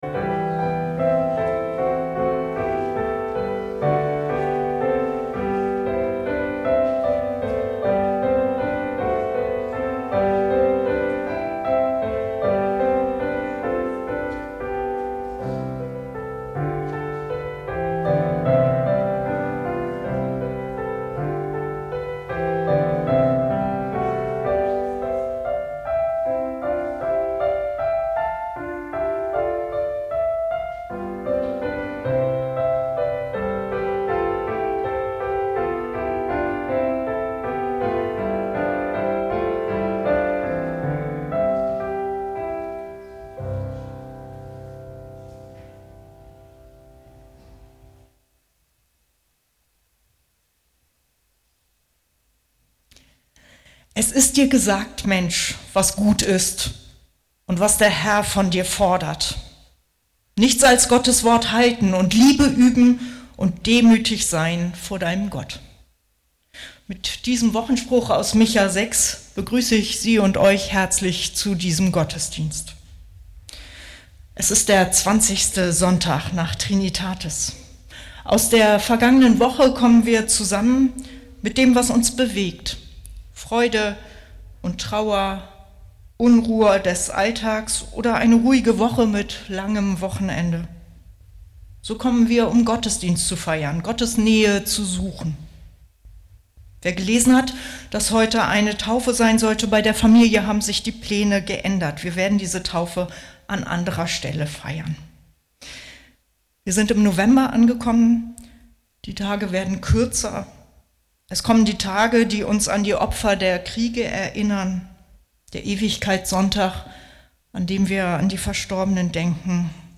Gottesdienst - 02.11.2025 ~ Peter und Paul Gottesdienst-Podcast Podcast